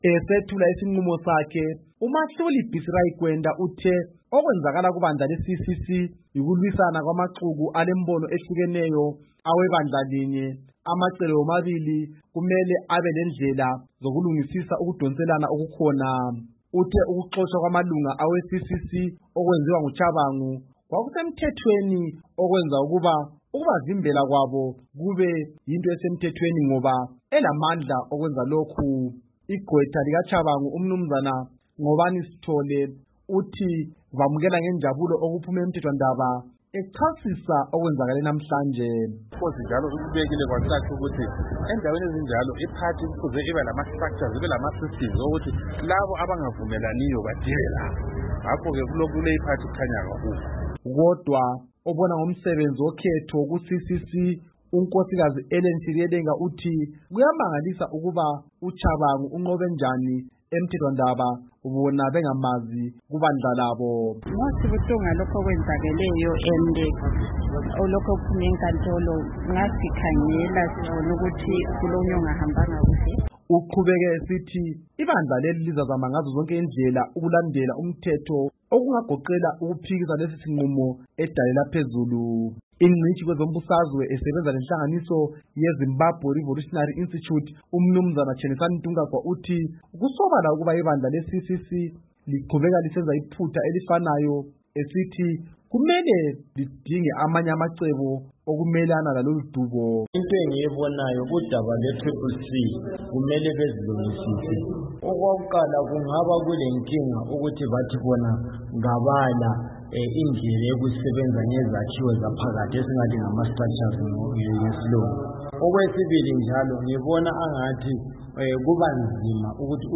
Udaba lweCCC